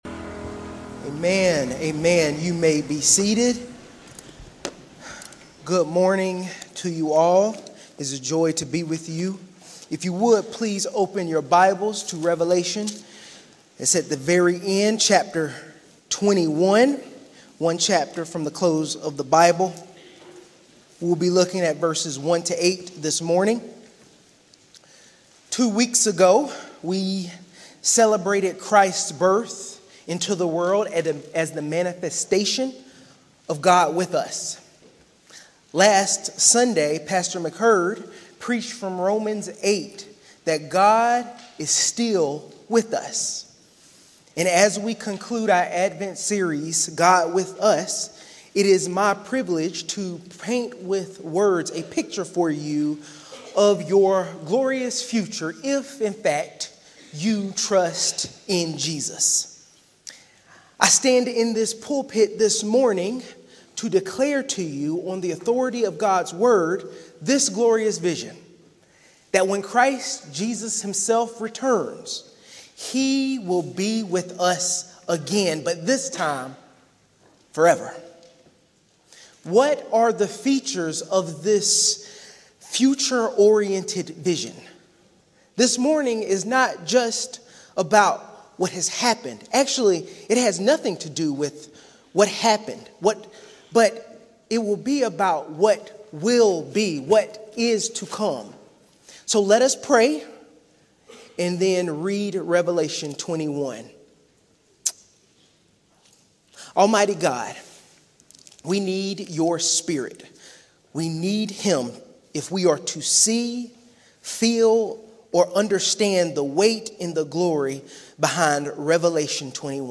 Sermons - First Presbyterian Church of Augusta